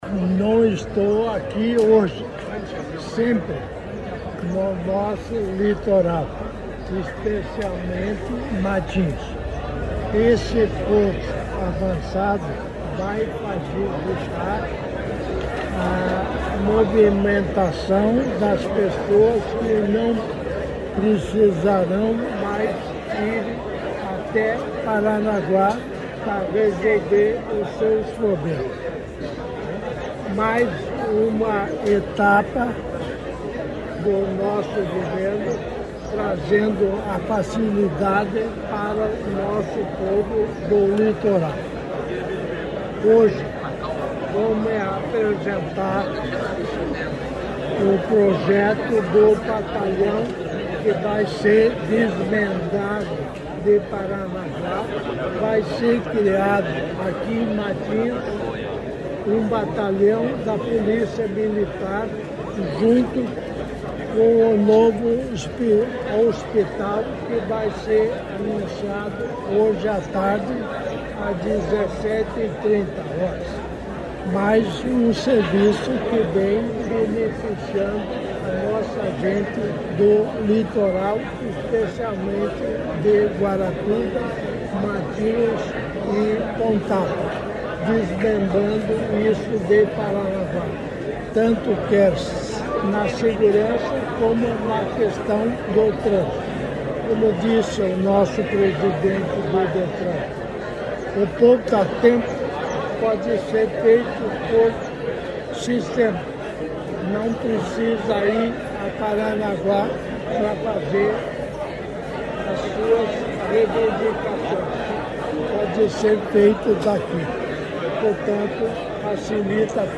Sonora do vice-governador Darci Piana sobre o novo Posto Avançado do Detran